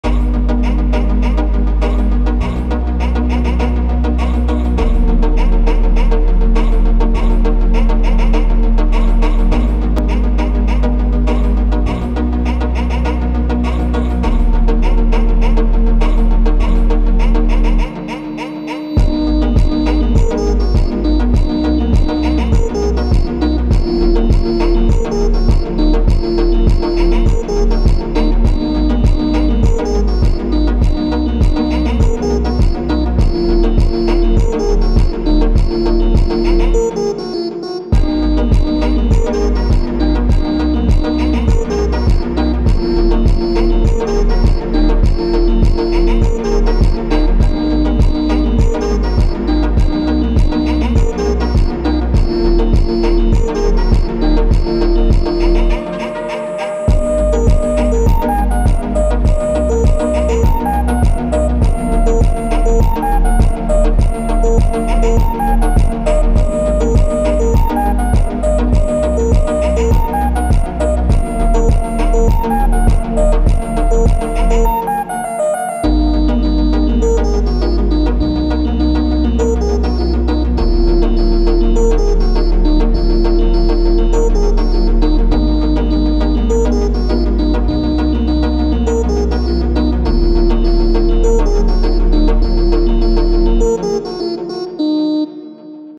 فانک
ماشینی